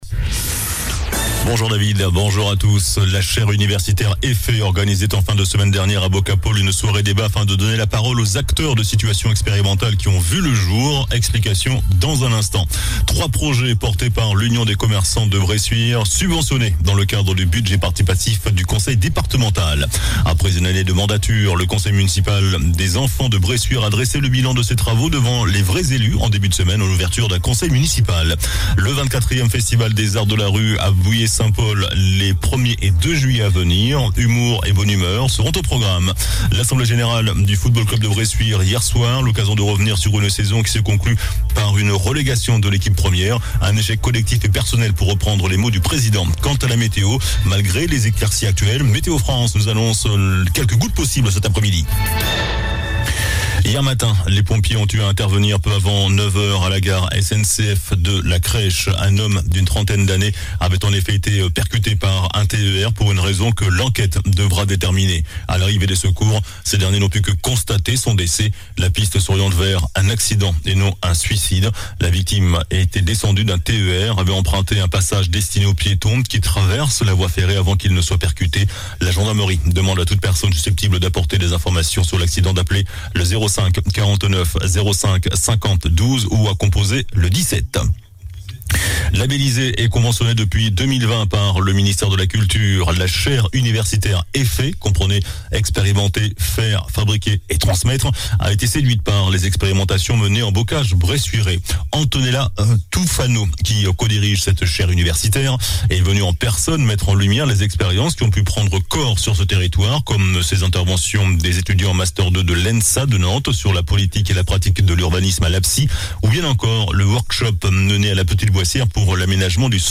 JOURNAL DU JEUDI 23 JUIN ( MIDI )